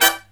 HIGH HIT10-L.wav